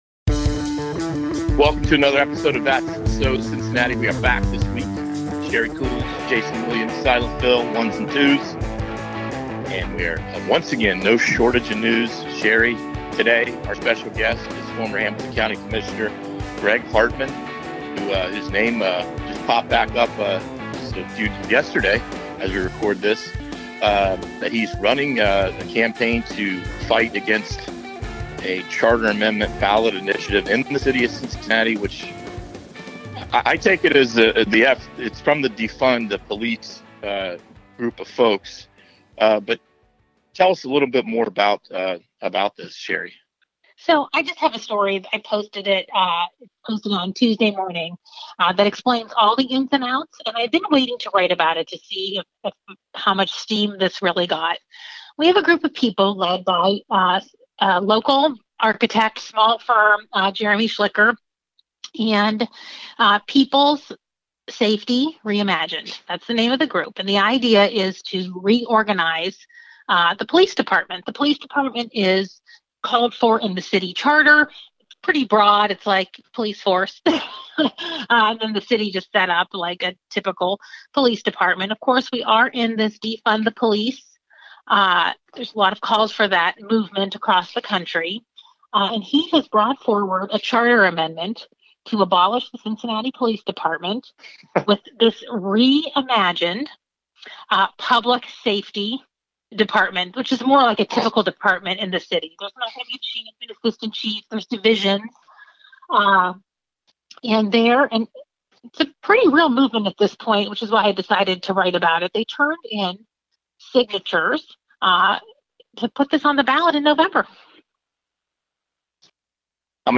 Back in the local political spotlight to lead the GOP's campaign to kill a potential ballot initiative to disband the Cincinnati Police , Hartmann this week reflected on his criticism of Trump and opened up about his current feelings about the president in a wide-ranging interview on The Enquirer's That's So Cincinnati podcast.